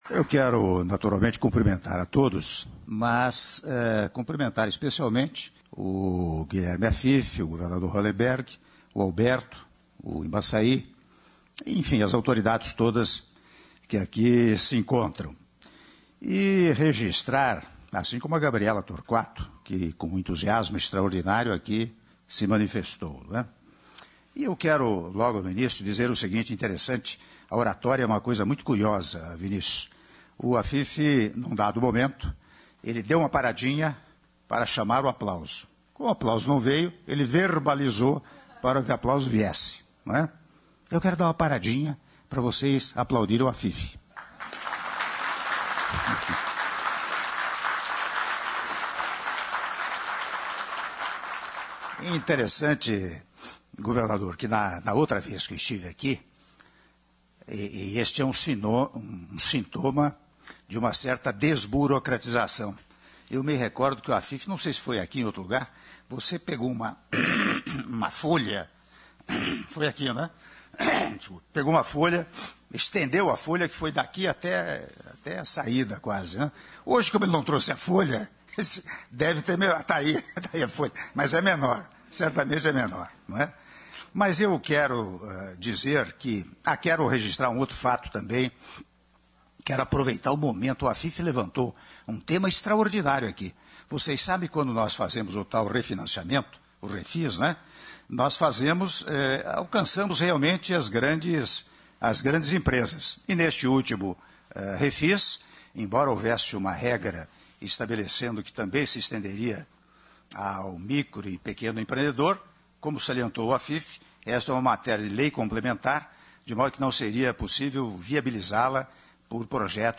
Áudio do discurso do Presidente da República, Michel Temer, durante cerimônia de Abertura da Semana Global do Empreendedorismo – SGE 2017 - (11min0s) - Brasília/DF